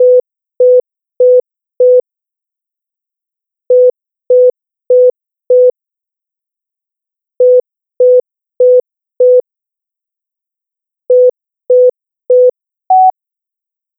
Um das Lernverhalten von Feten und Neugeborenen zu untersuchen, wird die magnetische Hirnaktivität gemessen, während die kleinen Studienteilnehmerinnen und -teilnehmer eine zufällige oder geordnete Abfolge von Tönen hören.
Diese Tonmuster bzw. Tonabfolgen bilden eine Regel.